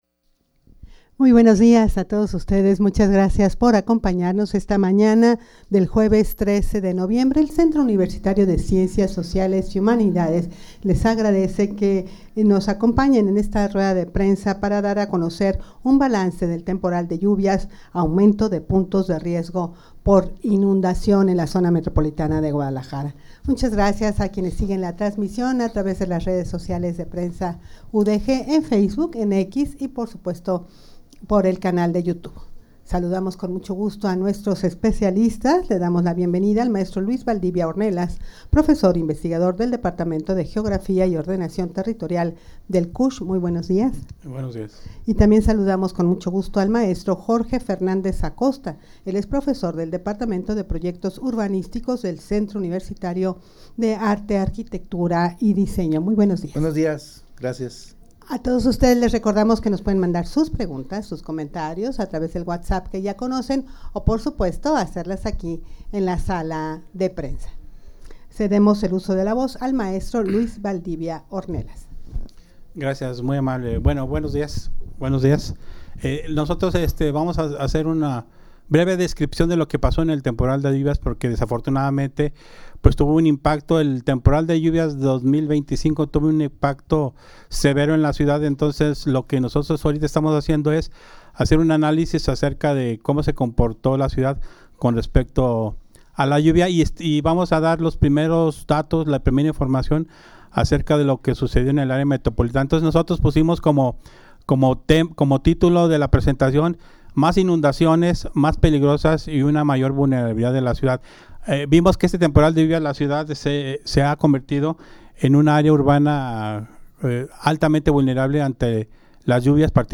Audio de la Rueda de Prensa
rueda-de-prensa-para-dar-a-conocer-un-balance-del-temporal-de-lluvias-aumento-de-puntos-de-riesgo-por-inundacion.mp3